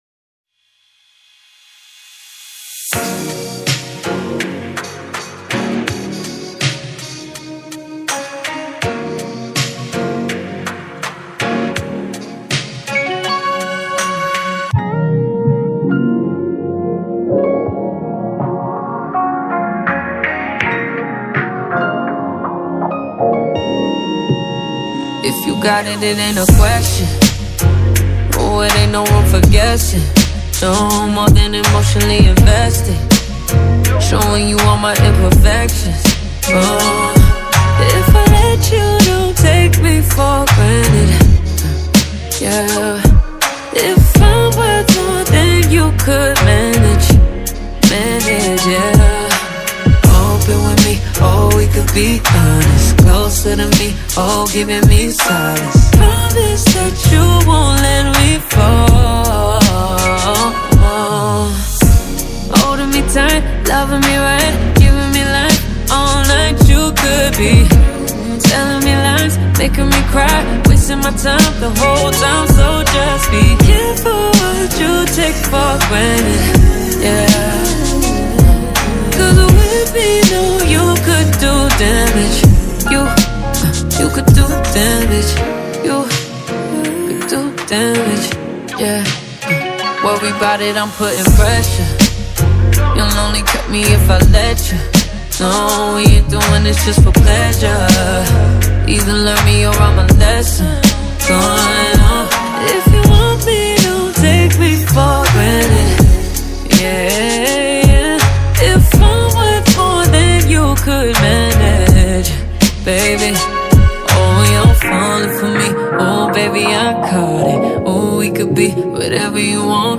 is a soulful R&B track